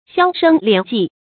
銷聲斂跡 注音： ㄒㄧㄠ ㄕㄥ ㄌㄧㄢˇ ㄐㄧˋ 讀音讀法： 意思解釋： 猶言銷聲匿跡。